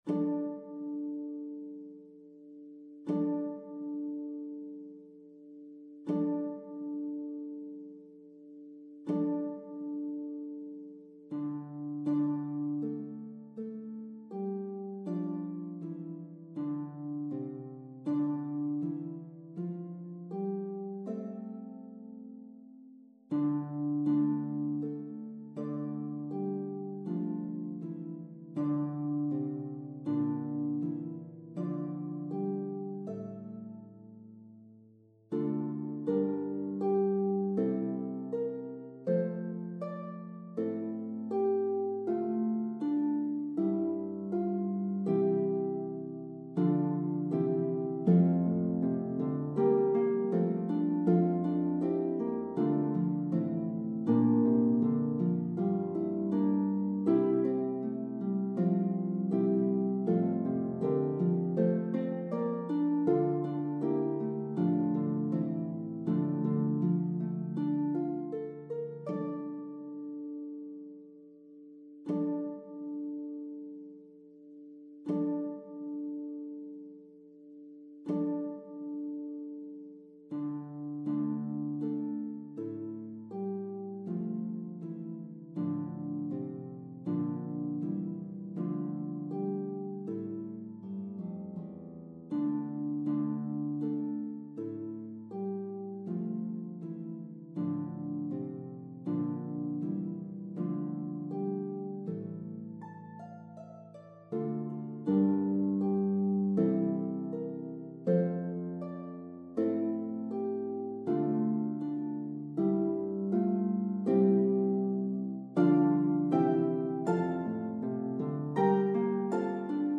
traditional English carol